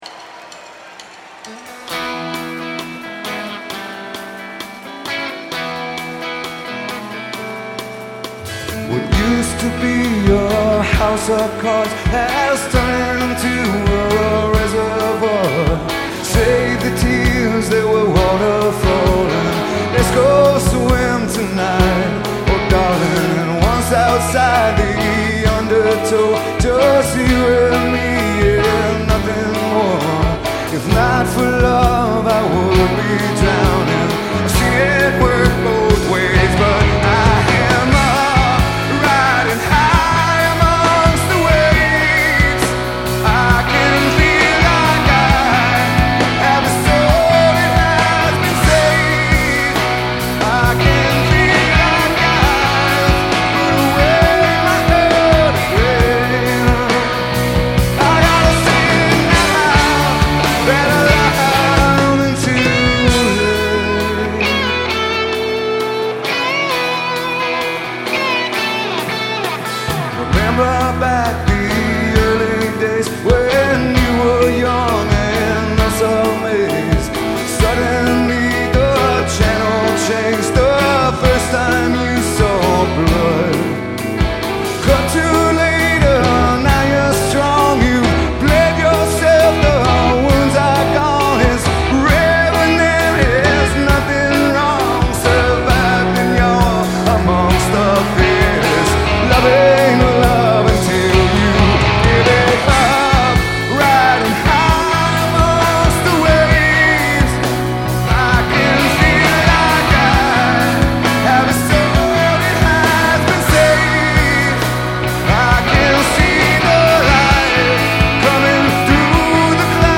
Live from Philadelphia!